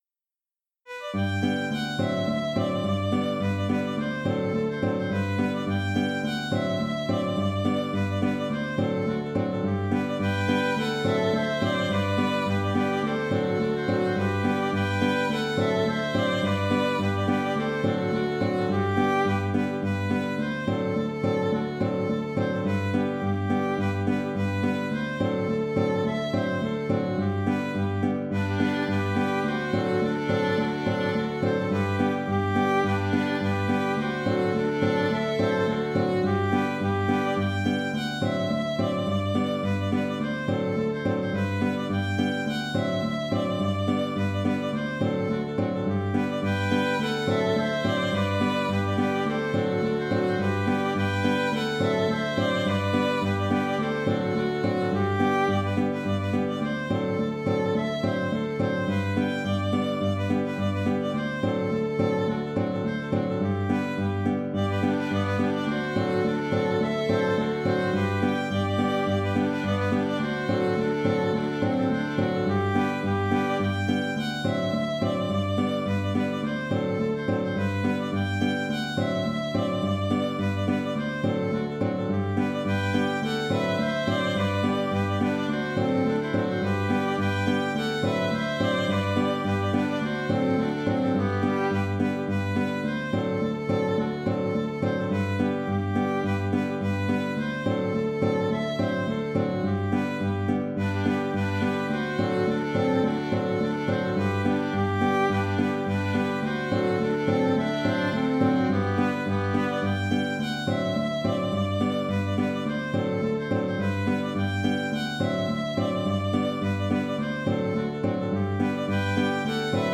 Tournicote (La) (Polka piquée) - Compositions
Cette polka assez simple doit être jouée vigoureusement et assez rapidement.
Trois phrases A, B, et C. La phrase A joue le rôle d’un refrain. Les phrases B et C alternent alors, la C étant en fait une variante de la B. Il y a deux versions du contre-chant assez proches, la première étant un peu plus simple.
Dans le fichier audio, les deux versions se suivent.